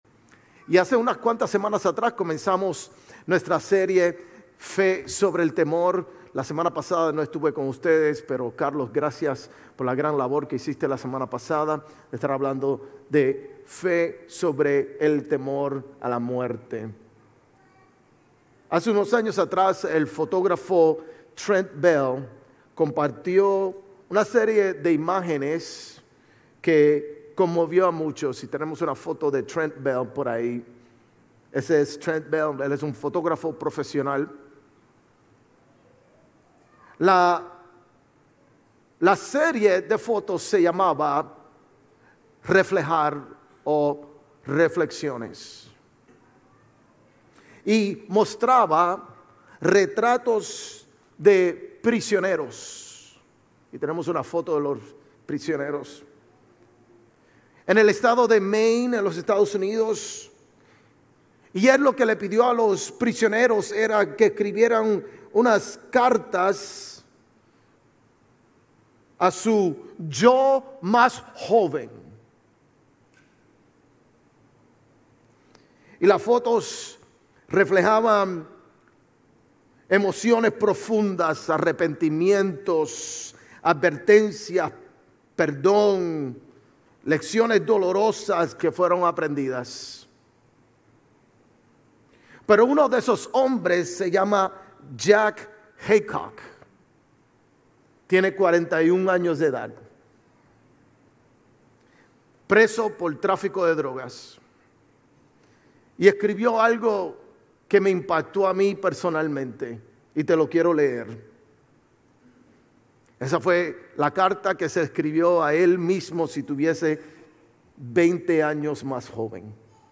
Sermones Grace Español 6_11 Grace Español Campus Jun 02 2025 | 00:39:28 1x 00:00 / 00:39:28 Subscribe Share RSS Feed Share Link Embed